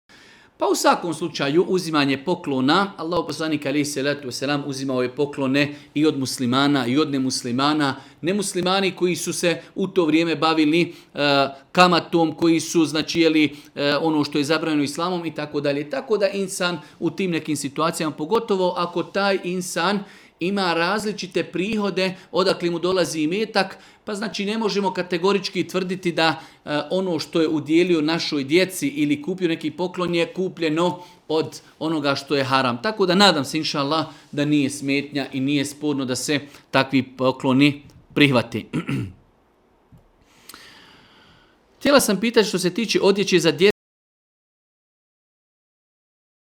Audio isječak odgovora Tvoj web preglednik ne podrzava ovaj fajl, koristi google chrome.